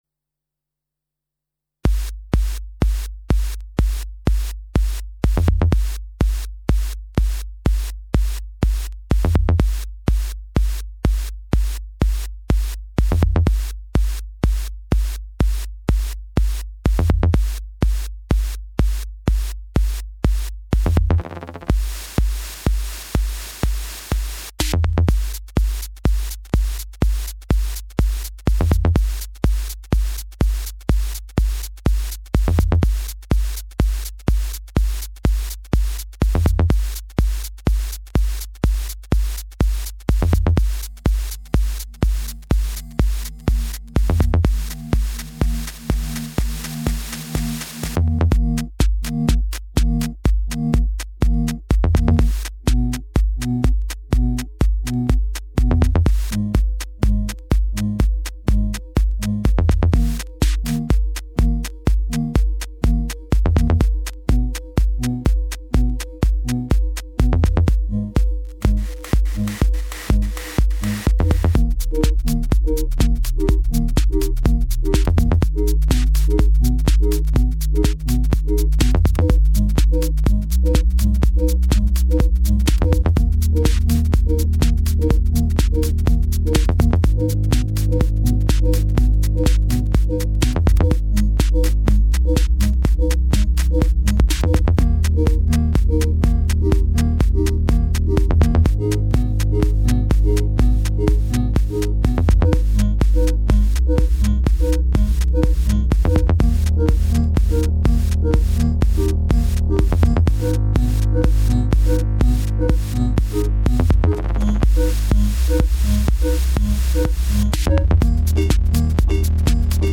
House Electro-House